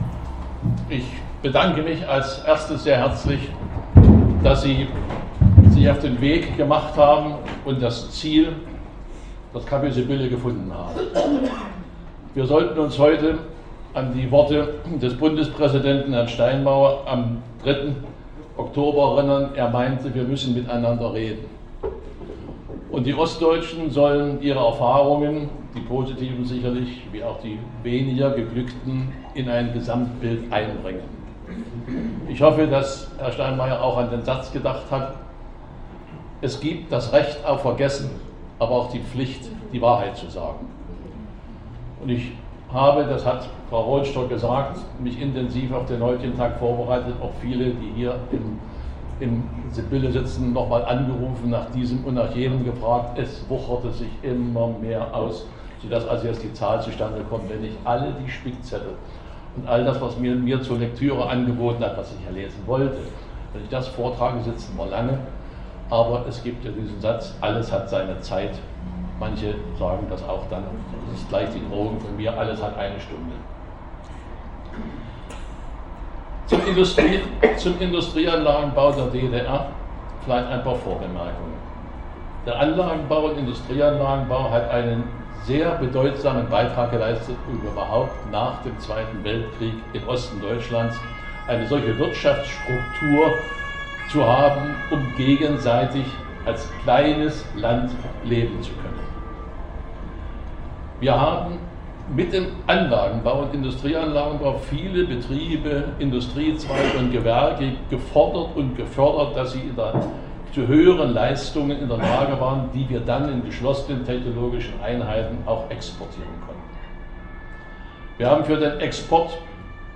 Die letzte Veranstaltung im Café Sibylle: